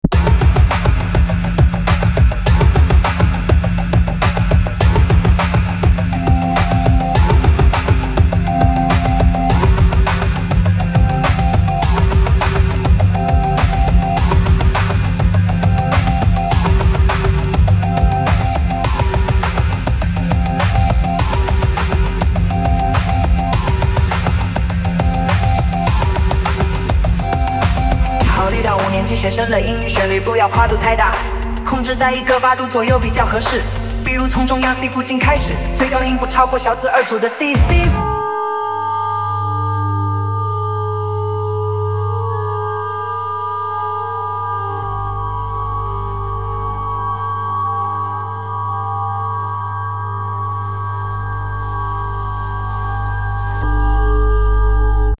2. 音域：考虑到五年级学生的音域，旋律不要跨度太大，控制在一个八度左右比较合适。
人工智能生成式歌曲